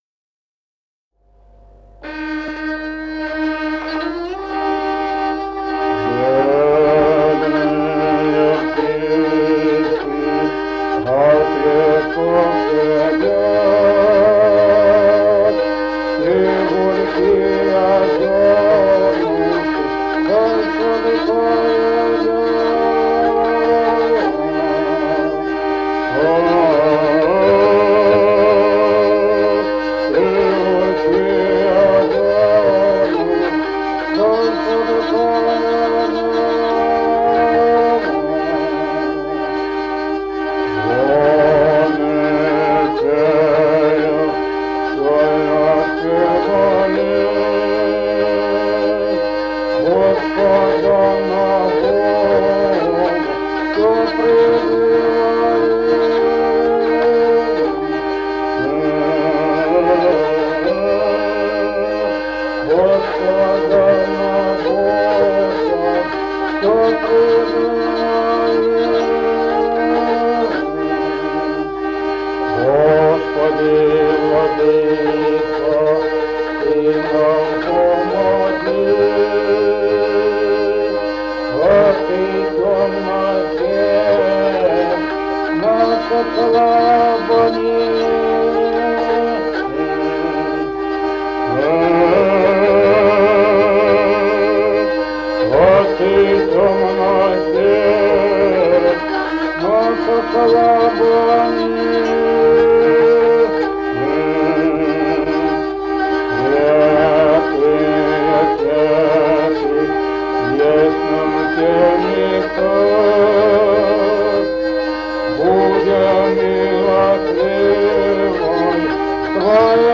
Народные песни Стародубского района «Бедные птички», духовный стих.